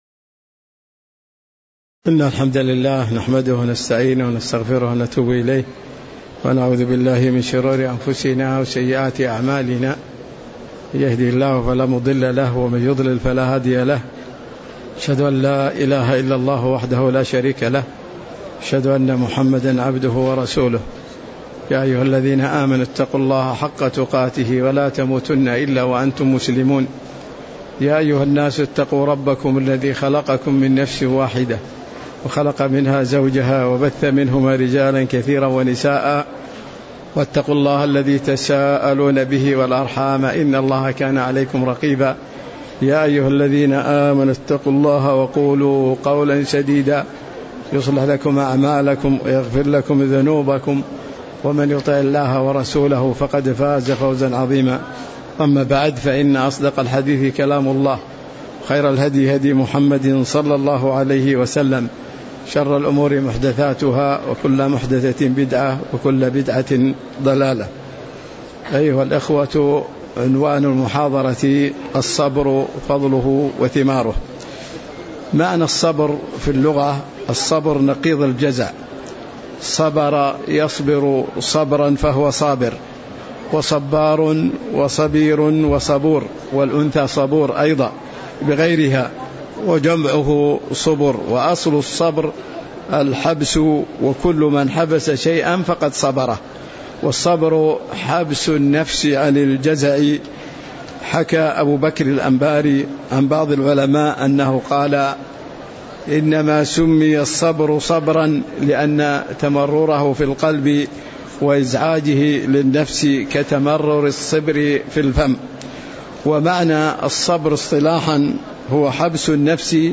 تاريخ النشر ١٣ ربيع الثاني ١٤٤٤ هـ المكان: المسجد النبوي الشيخ